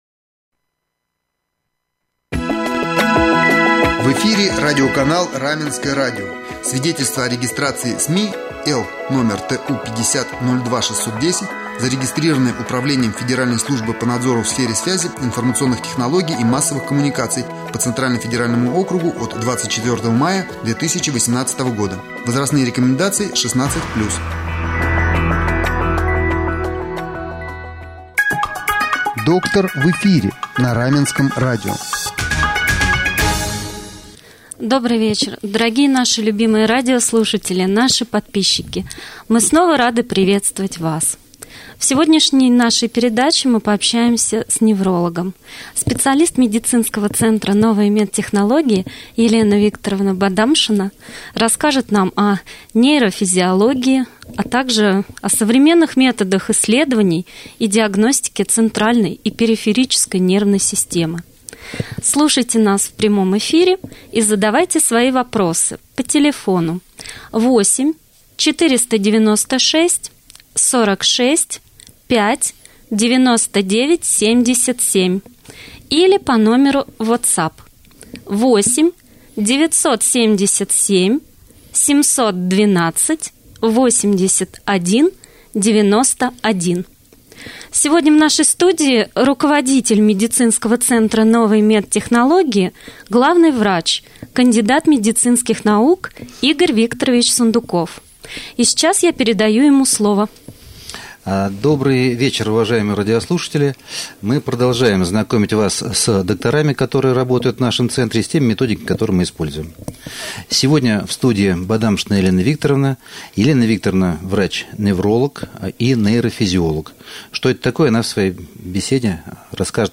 В передаче «Доктор в эфире» 19 мая мы пообщались с неврологом